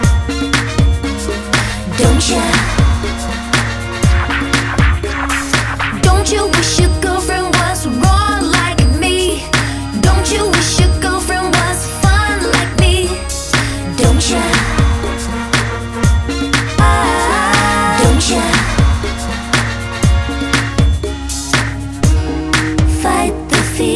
no Backing Vocals R'n'B / Hip Hop 3:35 Buy £1.50